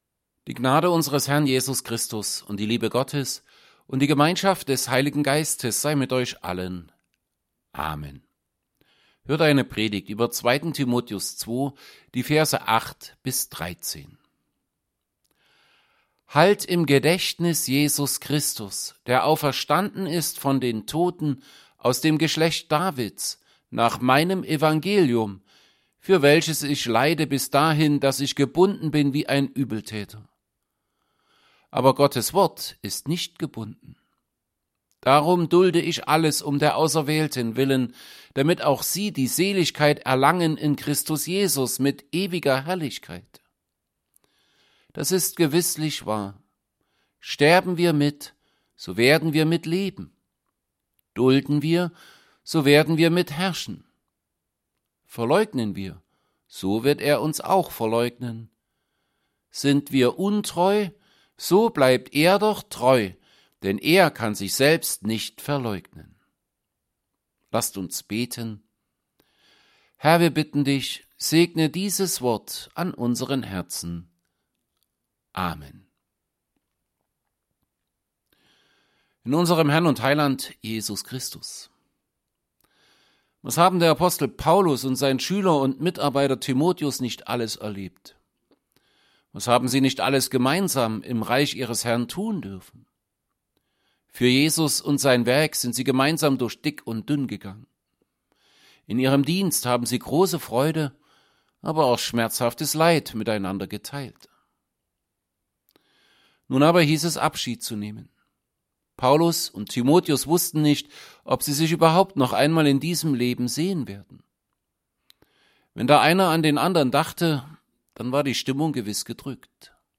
Predigt_zu_2_Timotheus_2_8b13.mp3